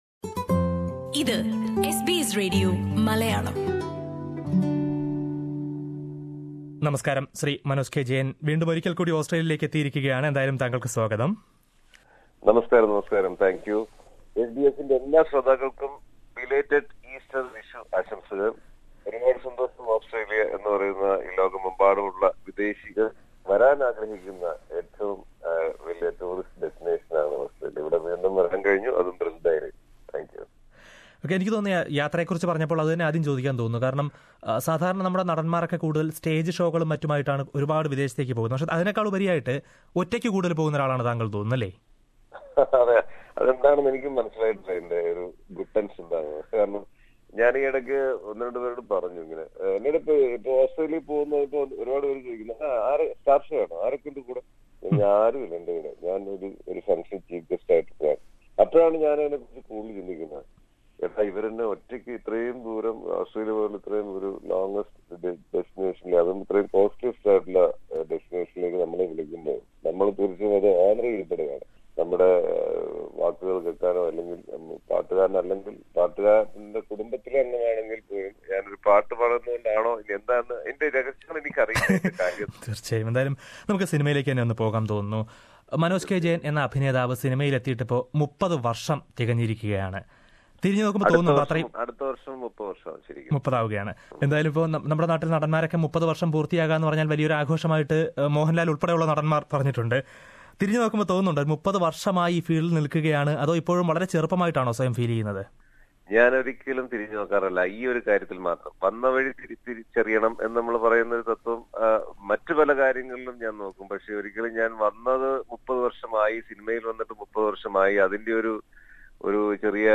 Malayalam actor Manoj K. Jayan, who completes three decades in acting career, talks to SBS Malayalam Radio during his recent visit to Australia.